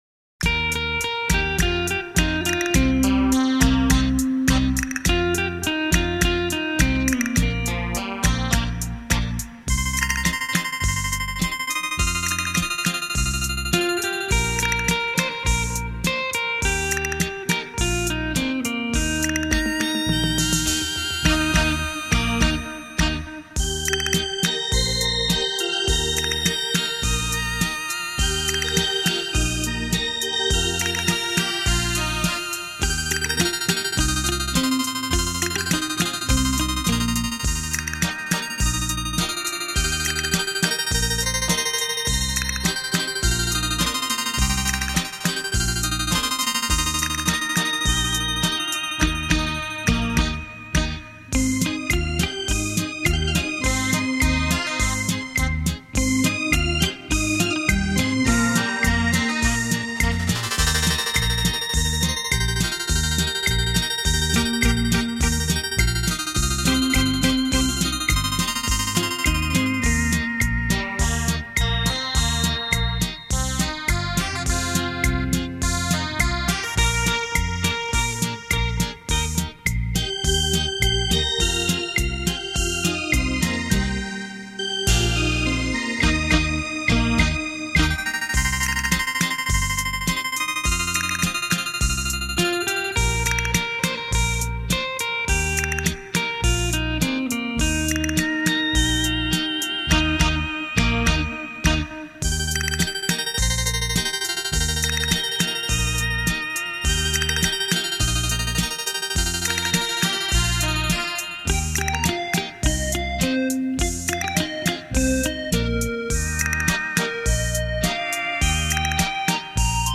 电子琴诠经典 表现另一种风情